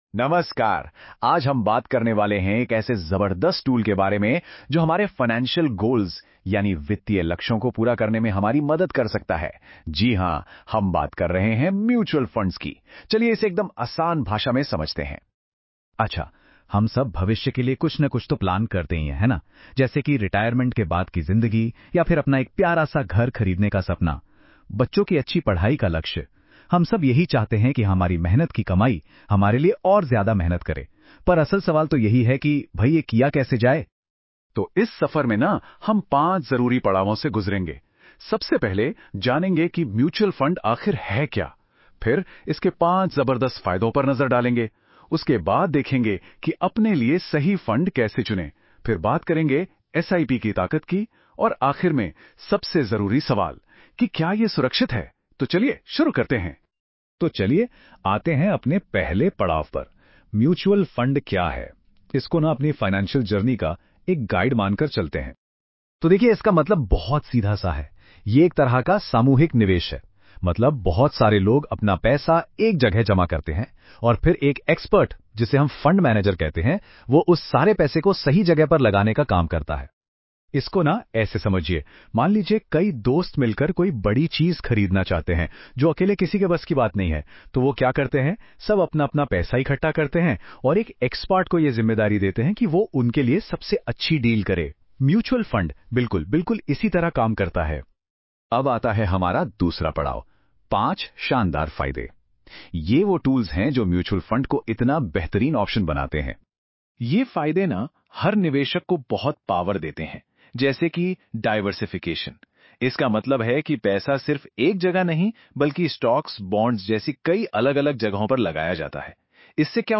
Before you start reading, listen to a short Hindi audio overview of this post — it gives you a quick idea about the topic in just a minute.